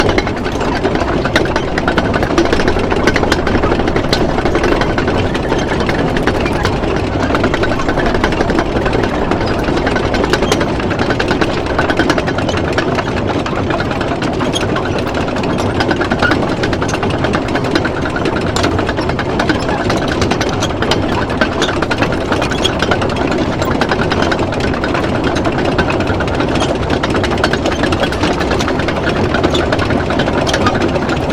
tank-tracks-3.ogg